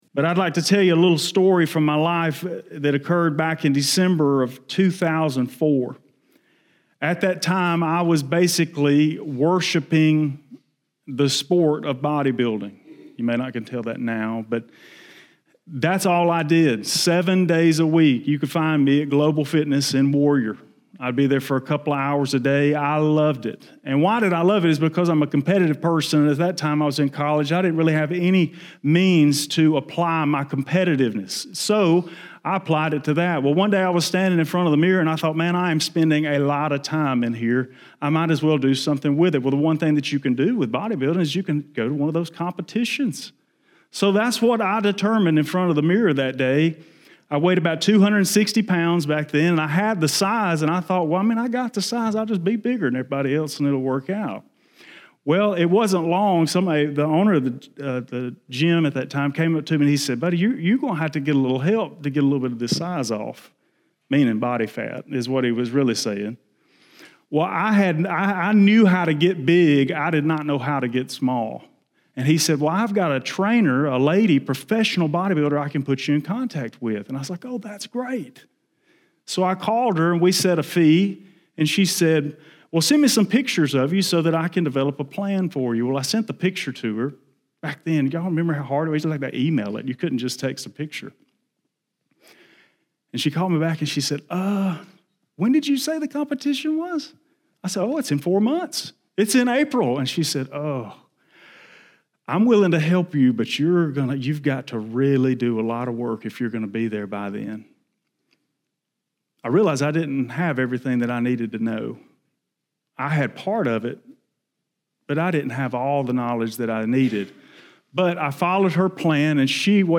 Learn more in this sermon.